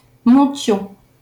Monthyon (French pronunciation: [mɔ̃tjɔ̃]